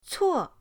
cuo4.mp3